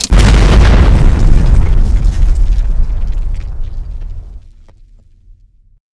c4_explode1.wav